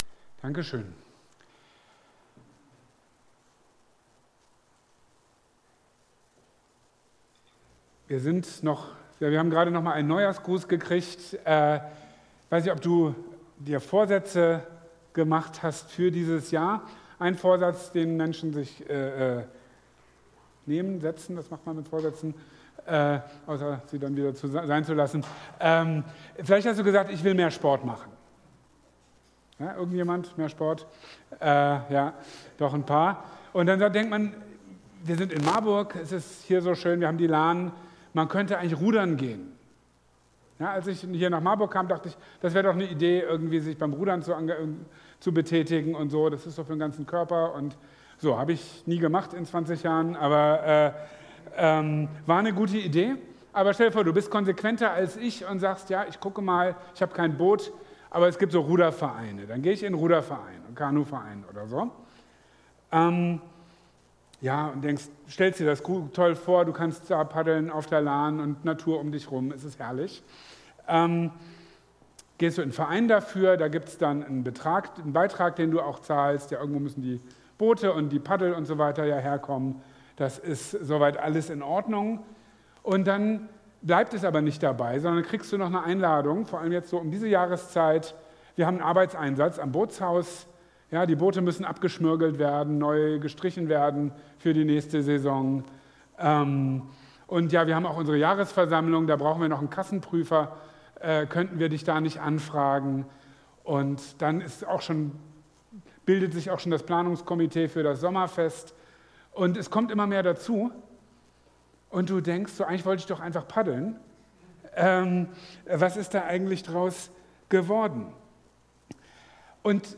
Wenn wir von der Herausforderung der Jesusnachfolge reden, fragen sich manche: Wie soll ich das auch noch in meinem vollen Alltag unterbringen? In seiner Predigt vom 20. Februar 2022 über Lukas 14,25-33